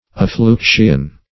Affluxion \Af*flux"ion\, n. The act of flowing towards; afflux.